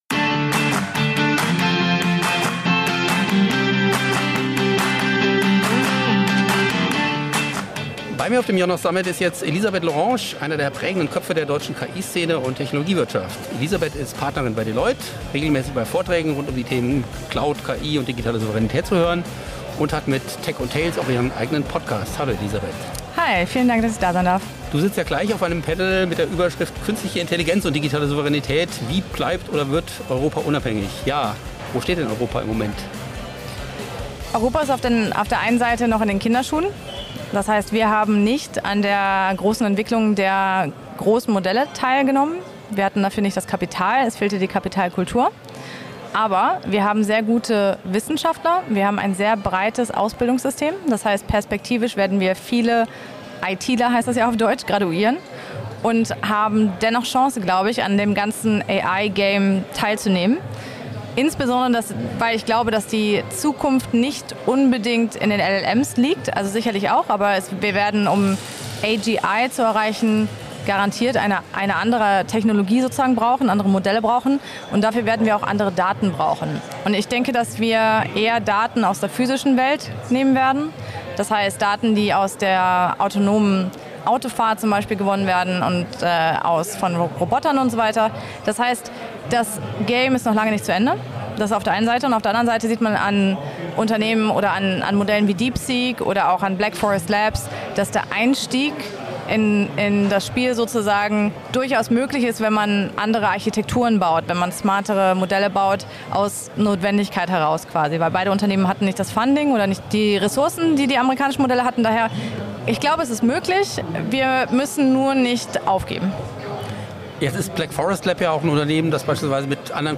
beim IONOS Summit 2025